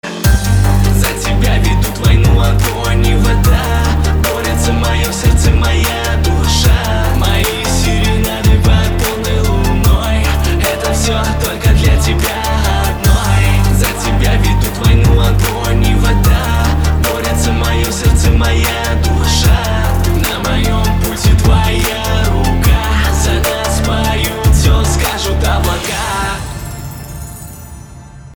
• Качество: 320, Stereo
лирика
Хип-хоп
русский рэп
романтичные